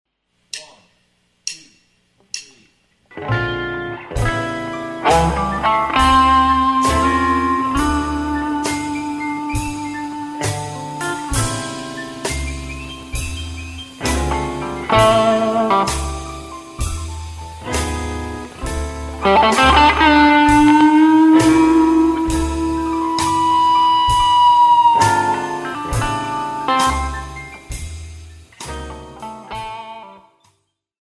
30 Sek. Soundschnipsel - Tokai mit Kitty
wo ich jetzt zwar nicht die Superrückkopplung habe, aber es ist so einen Fuß breit über der Grenze und das macht mir richtig Spaß, wenn man mit der Kopplung spielen kann.